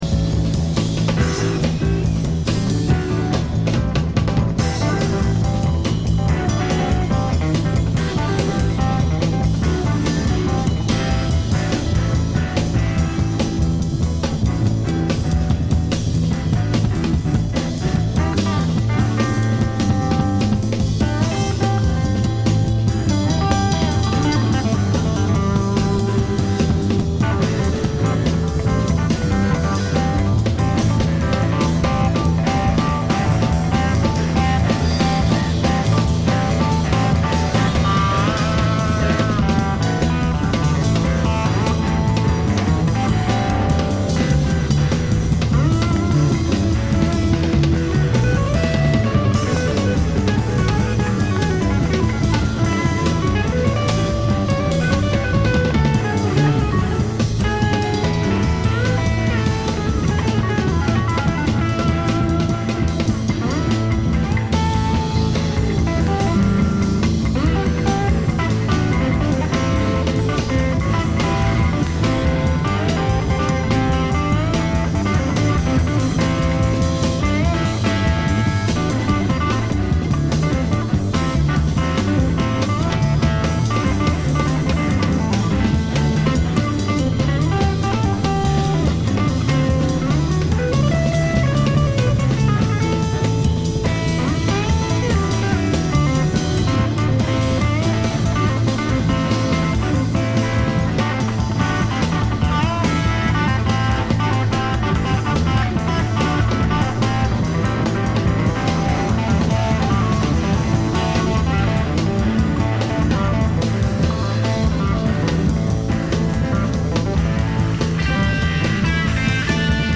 ライヴのAB面はチリプチは出ていると思うのですが音が音だけにほとんどわからない感じです。
試聴曲は現品からの取り込み音源です。
[B](live)